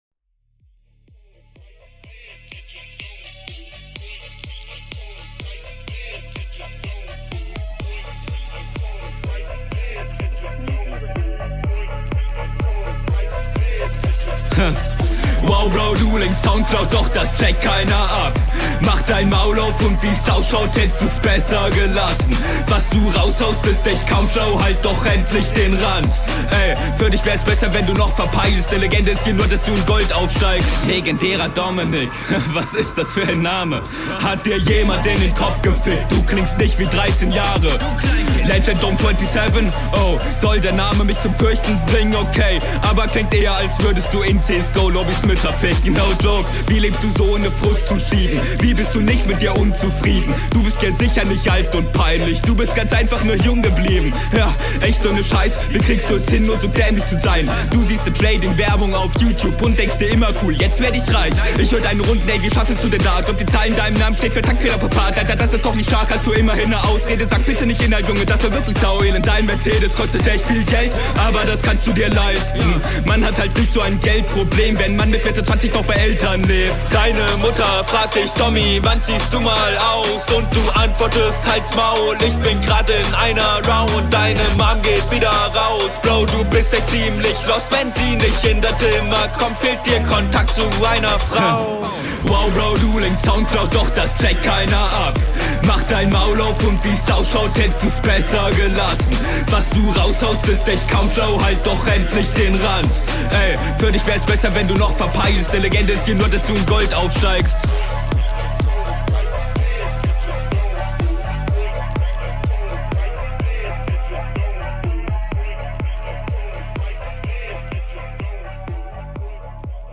Finde die Beatwahl in dem Battle zwar fragwürdig aber jedem das seine.